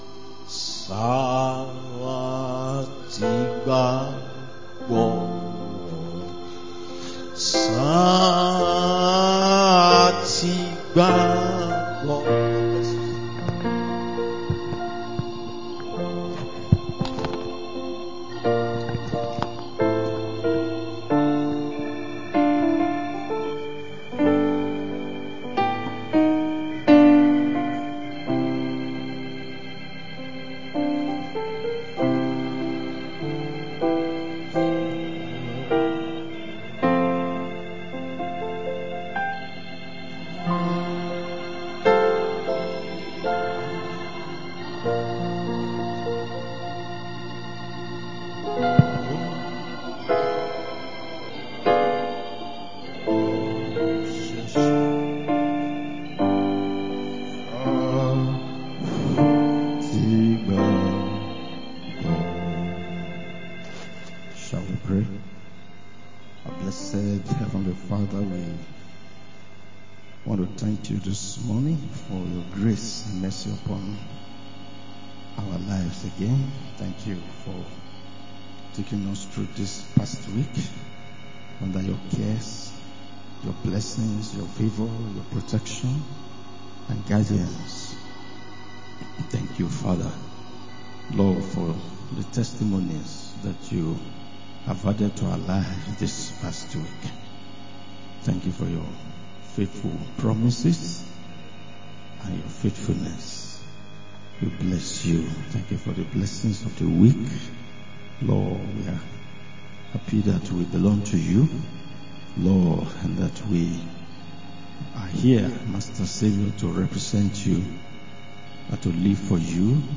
Adult Sunday School 28-09-25